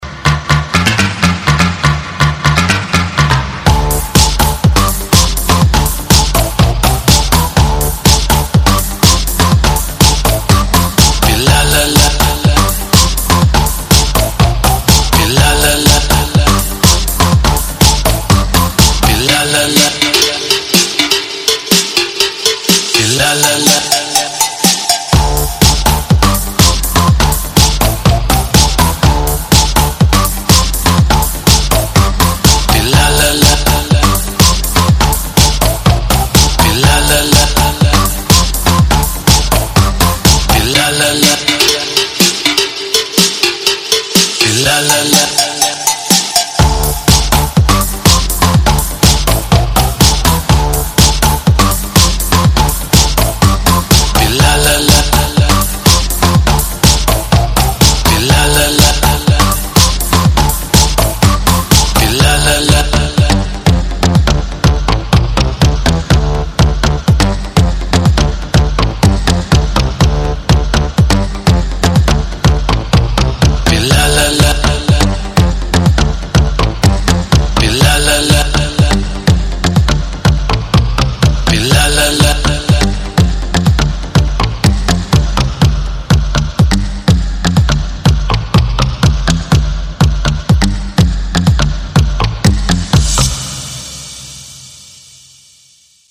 • Качество: 128, Stereo
клубные